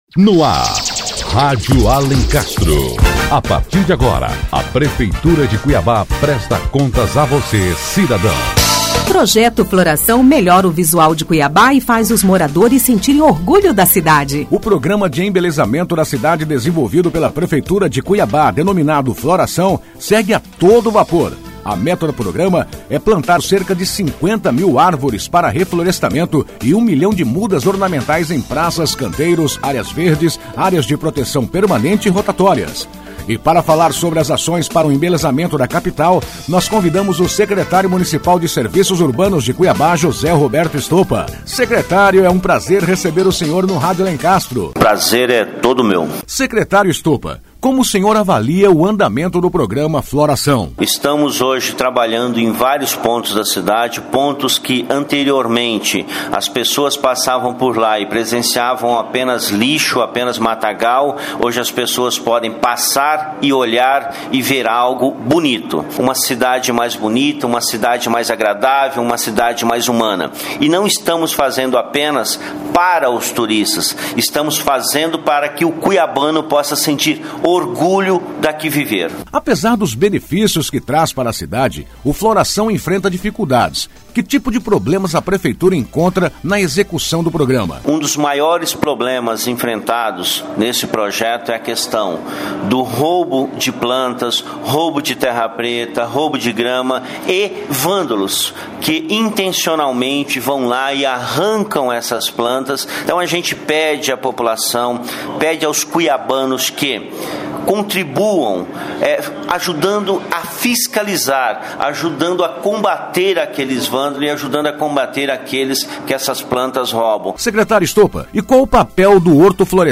A meta da Prefeitura é plantar cerca de 50 mil árvores para reflorestamento e 1 milhão de mudas ornamentais em praças, canteiros, áreas verdes, áreas de proteção permanente e rotatórias em toda cidade. E para falar mais sobre esta bela iniciativa convidamos o Secretário Municipal de Serviçps Urbanos, José Roberto Stopa. Confira a entrevista na íntegra.